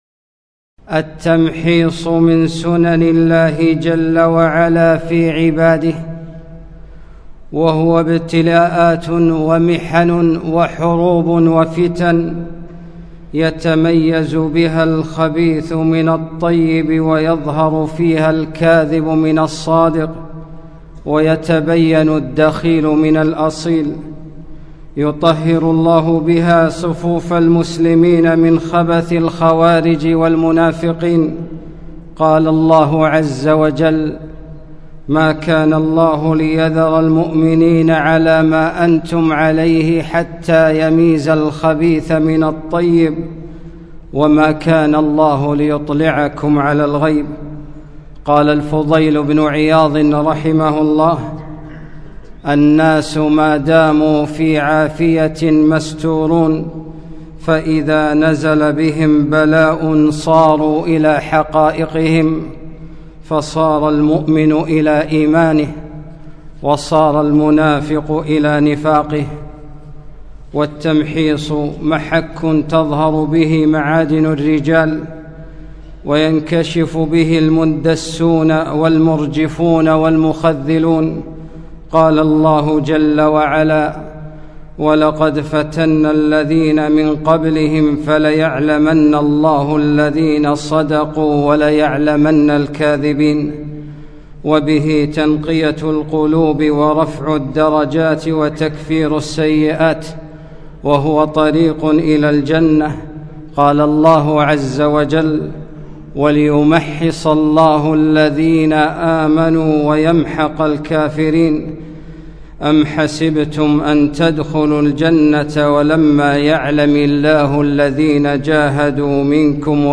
خطبة - التمحيص